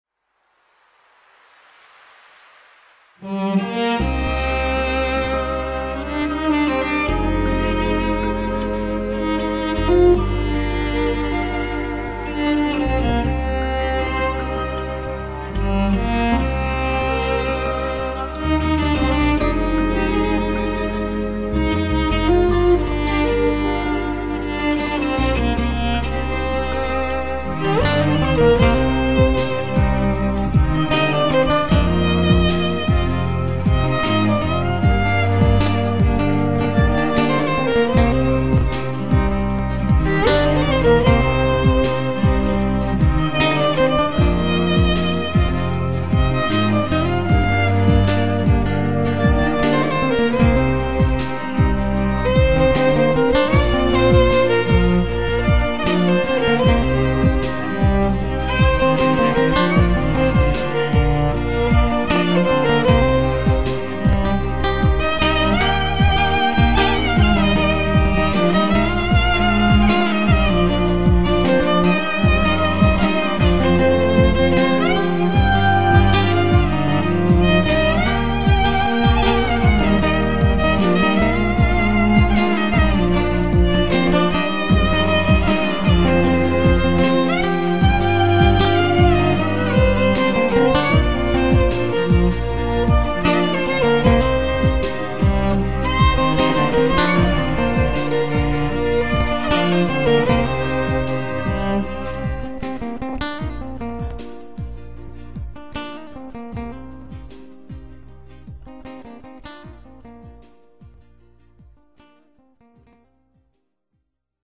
2 x Violin, Viola, Cello